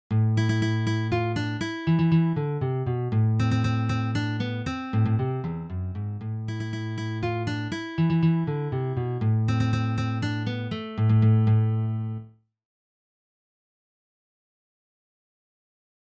Für Gitarre Solo
Sololiteratur
Gitarre (1)